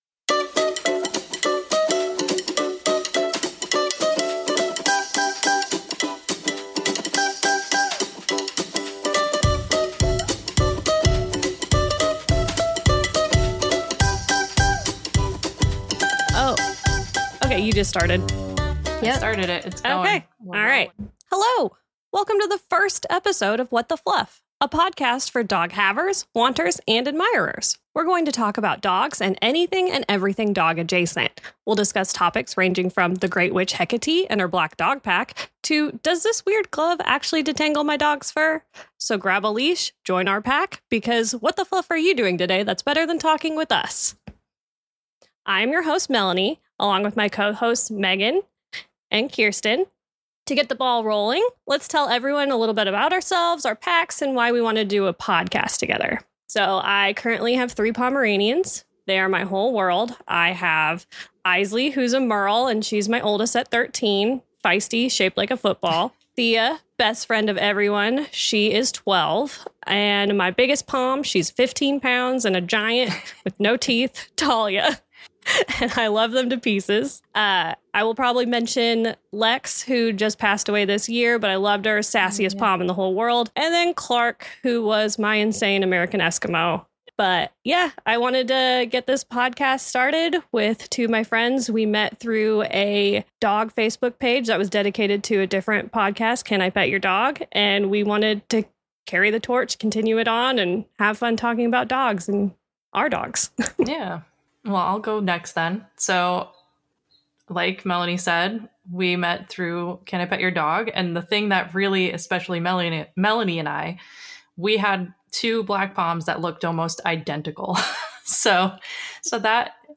We chat about how the three of us became friends and why we wanted to start a podcast about our absolute favorite topic.